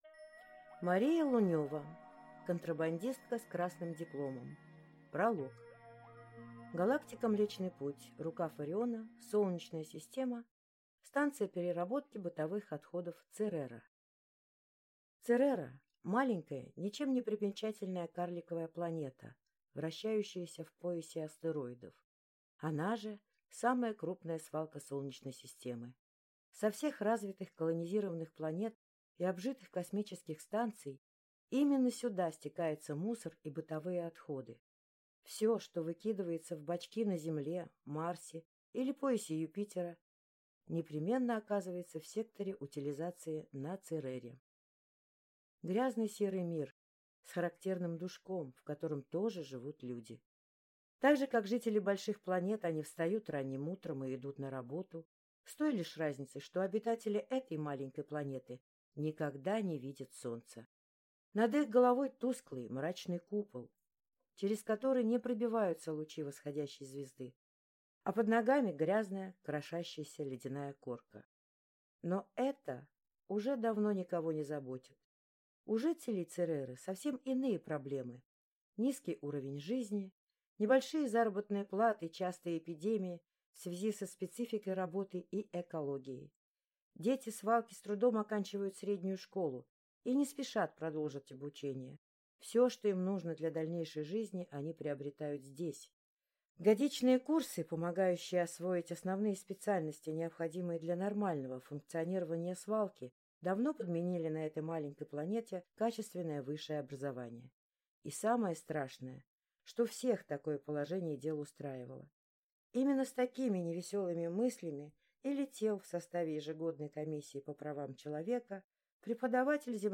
Аудиокнига Контрабандистка с красным дипломом | Библиотека аудиокниг